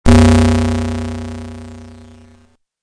These were all recorded as WAV's and converted to MP3's to save space.
A Loud Gong Sounds
gong.mp3